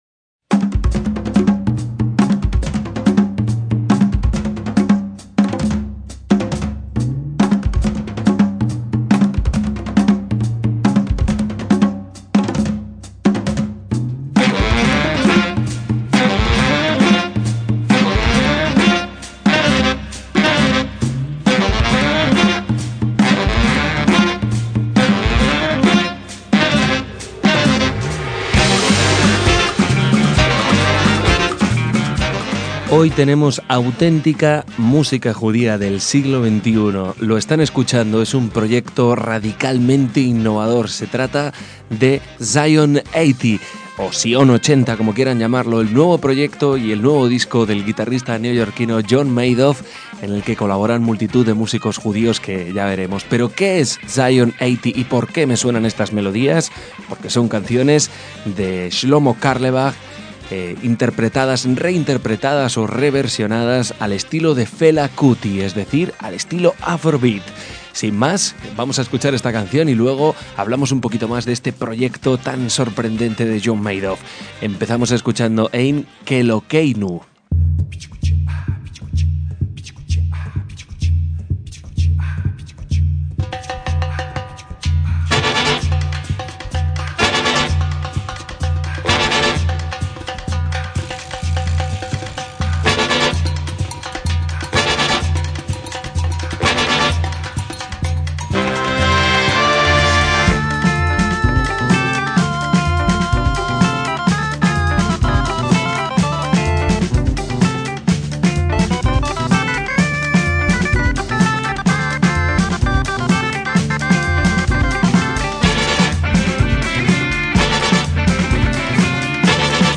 funk afrobeat
con una formación de 13 músicos